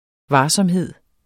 Udtale [ ˈvɑːsʌmˌheðˀ ]